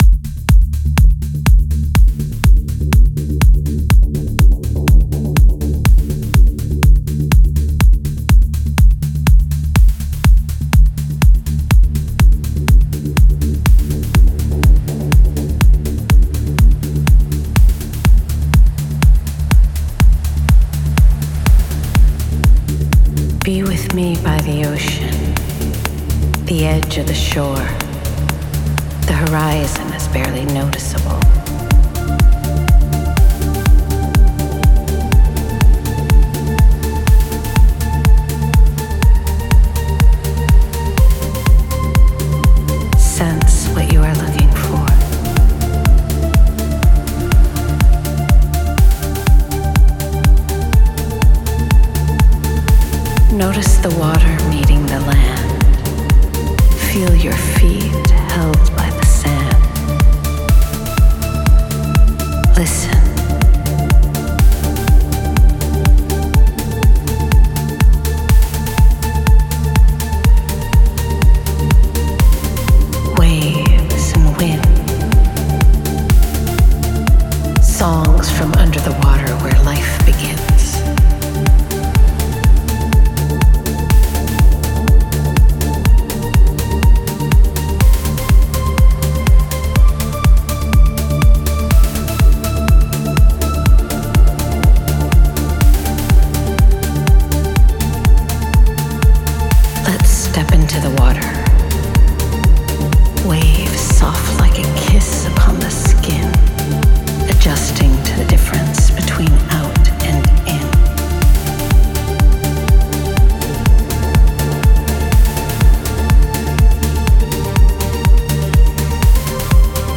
Progressive Trance